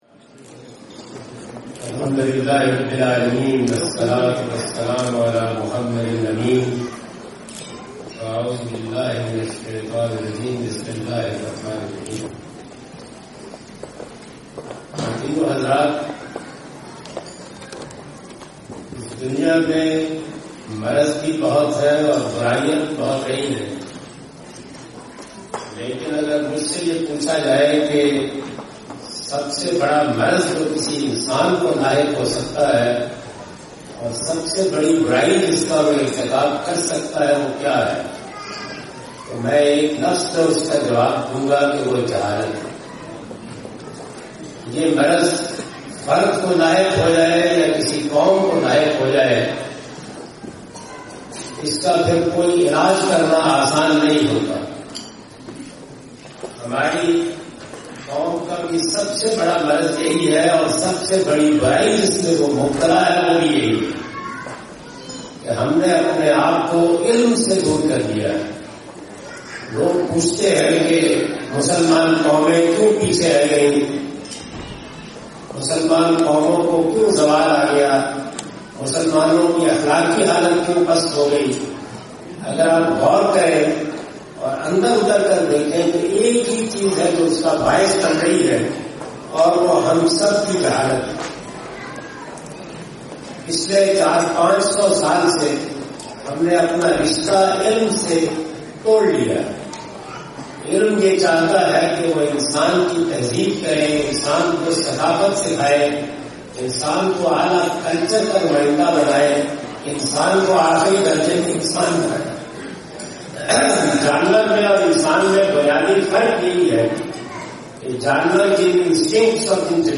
Javed Ahmad Ghamidi speaks on “The Real Virtue (Importance of Education)” in Seattle during his US visit in 2017.
جاوید احمد غامدی اپنے دورہ امریکہ 2017 کے دوران "حقیقی نیکی(لوگوں میں تعلیم و تعلم کی اہمیت اور ضرورت)" سے متعلق ایک تقریب سے خطاب کررہے ہیں۔